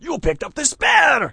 mp_youpickedupspare.wav